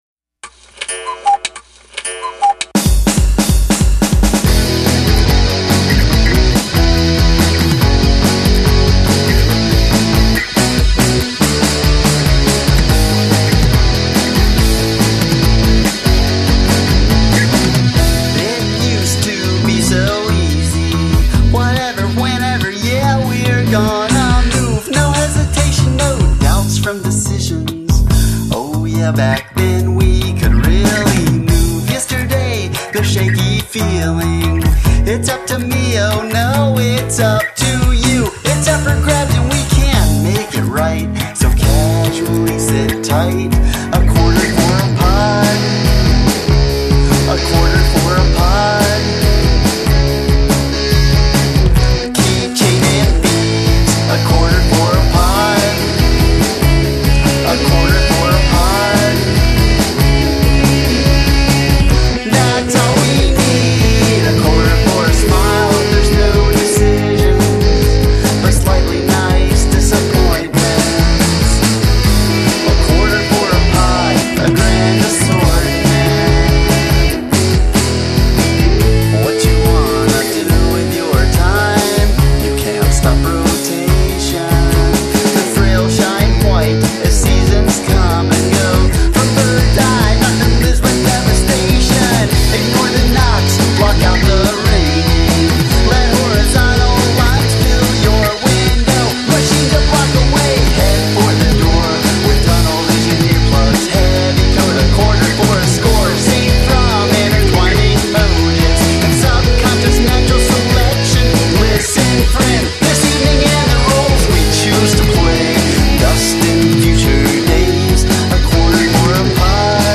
Quirkadelic Rock